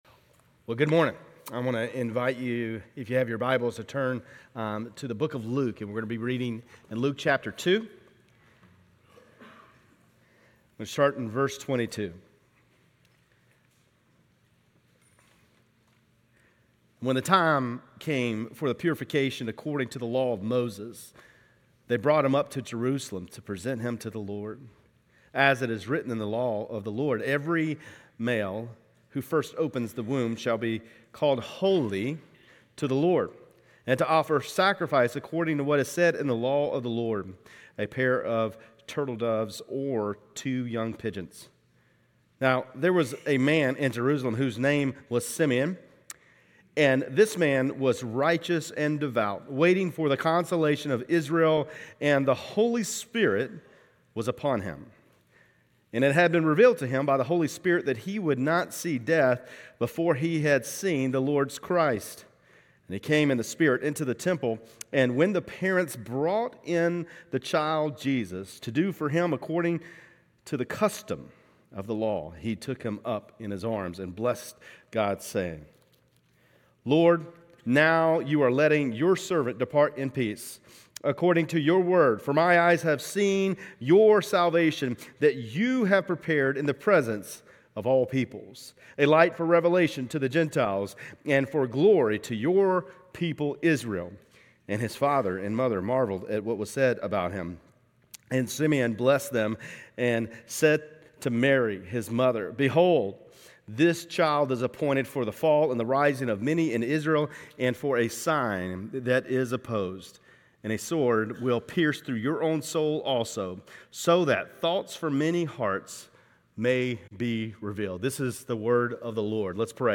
GCC-LD-Dec-17-Sermon.mp3